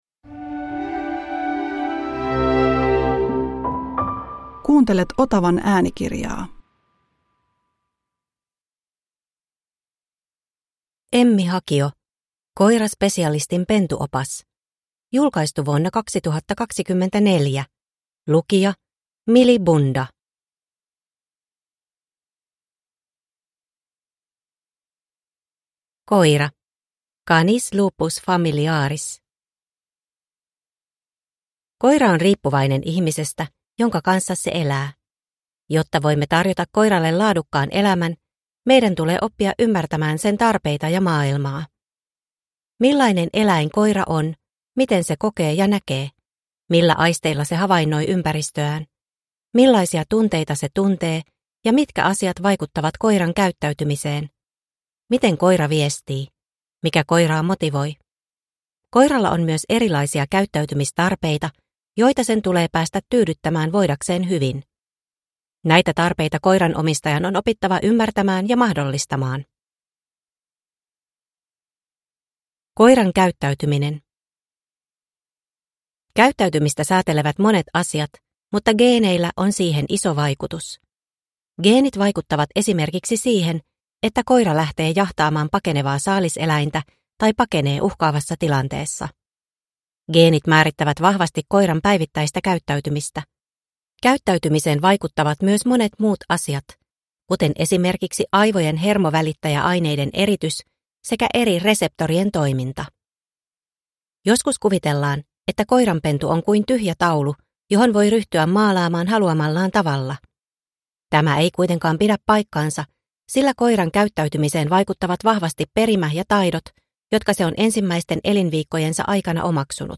Koiraspesialistin pentuopas – Ljudbok